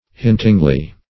hintingly - definition of hintingly - synonyms, pronunciation, spelling from Free Dictionary Search Result for " hintingly" : The Collaborative International Dictionary of English v.0.48: Hintingly \Hint"ing*ly\, adv.